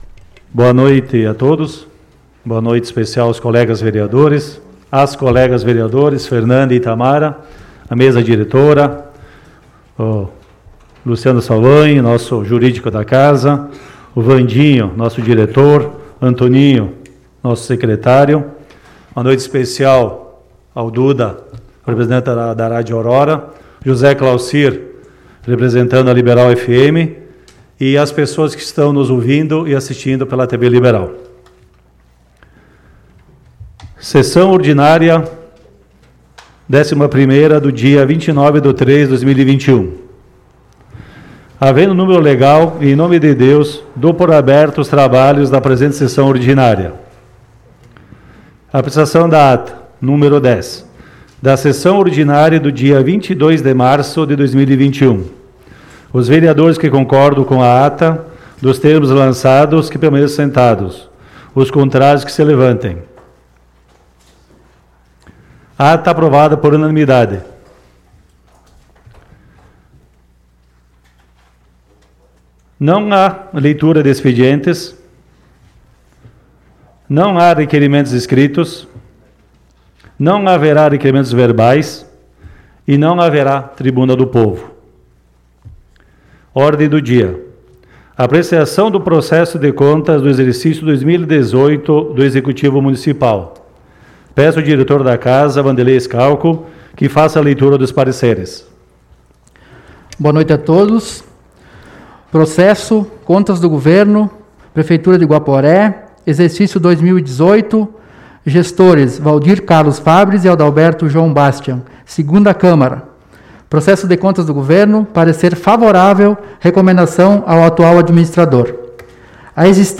Sessão Ordinária do dia 29 de Março de 2021 - Sessão 11